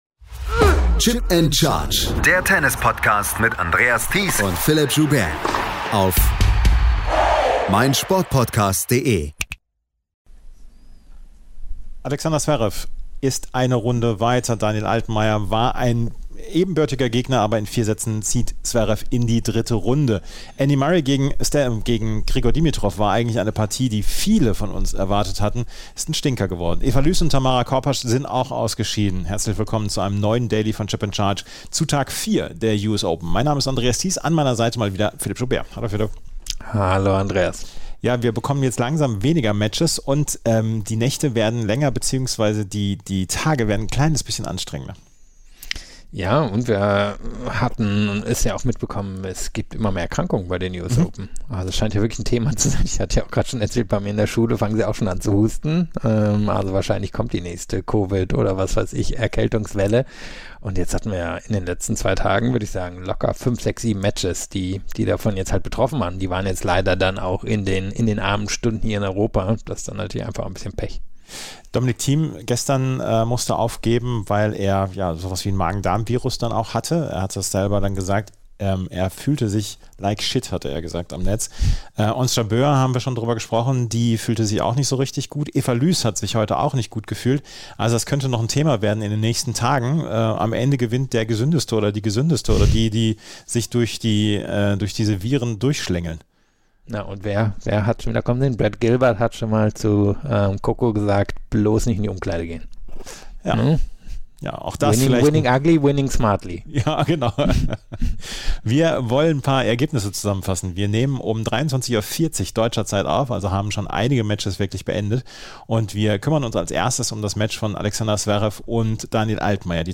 Wir waren in Salzburg vor Ort und haben uns mit dem Linkshänder zum Gespräch verabredet. Wie er mit dem Übergang zum Herrentennis und dem Erfolgsdruck umgeht, erfahrt ihr in unserer aktuellen Ausgabe des Challenger Corner.